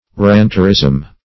Search Result for " ranterism" : The Collaborative International Dictionary of English v.0.48: Ranterism \Rant"er*ism\ (r[a^]nt"[~e]r*[i^]z'm), n. (Eccl.